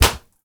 punch_blocked_01.wav